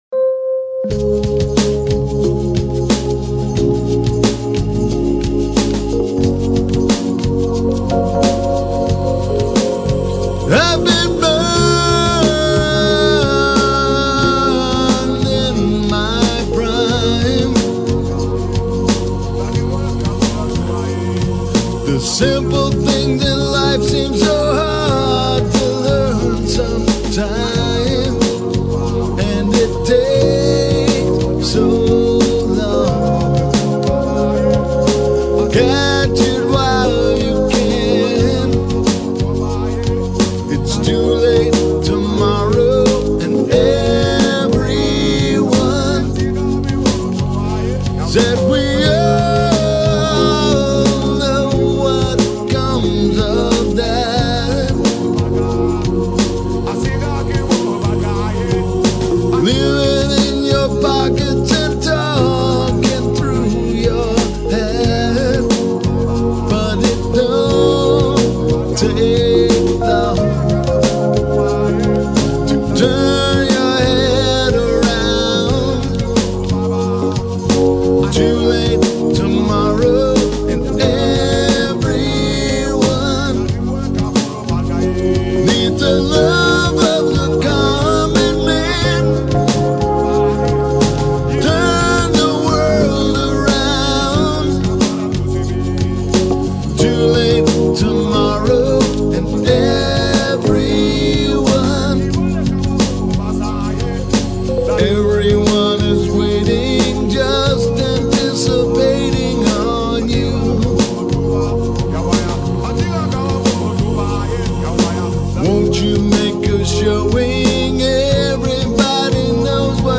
vocals, guitars, synths, drum programming
ghanese voice, percussion
rhodes
bass